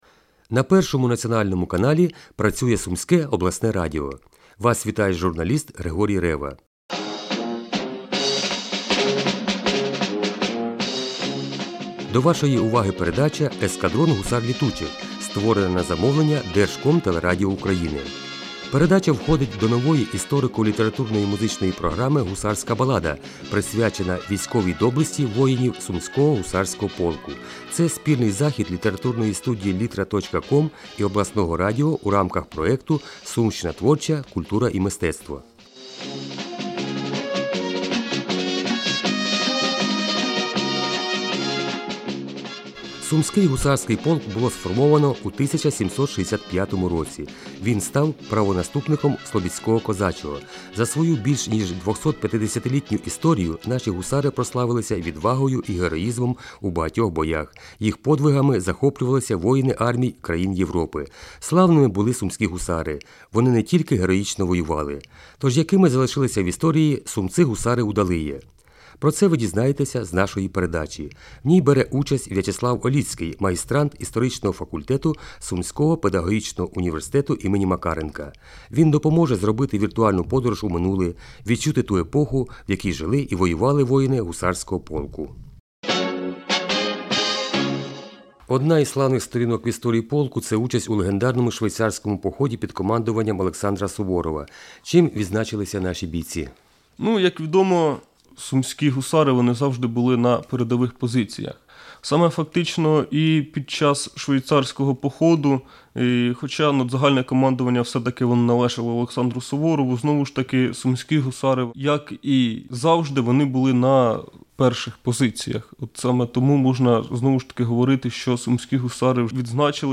"Гусарская баллада" на областном радио (2) (запись с эфира)